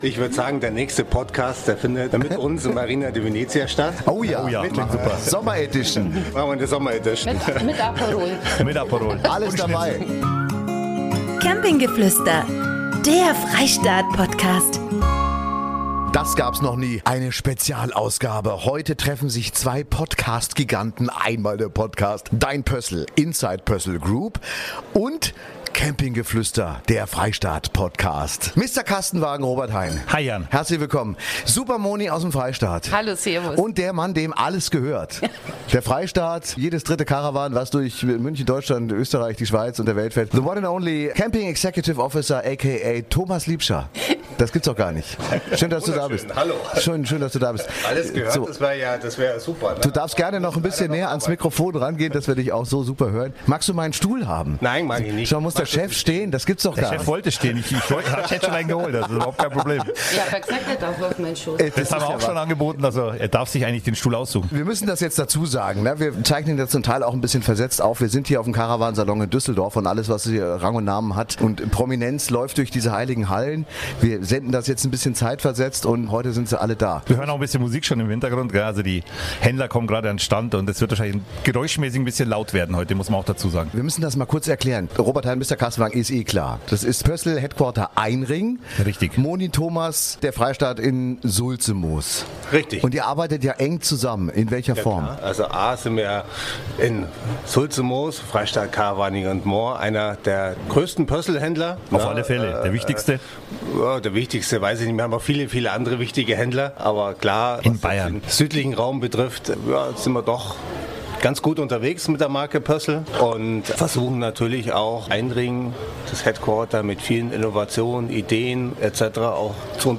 in einem Studio. Heute vereinen wir die beiden erfolgreichen Camper-Podcasts "Dein Pössl" und "Camping Geflüster".